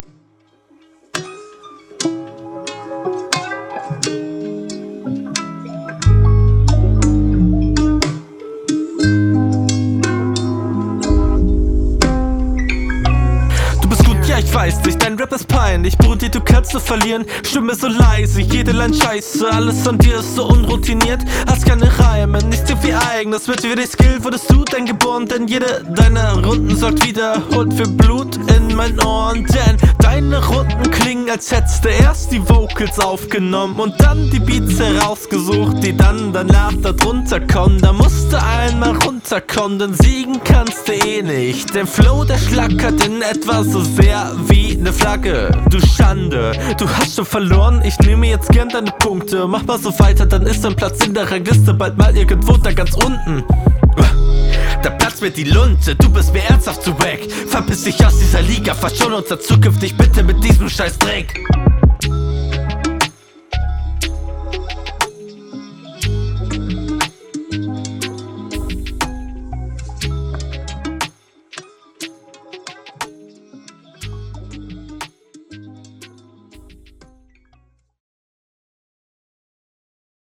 Battle Rap Bunker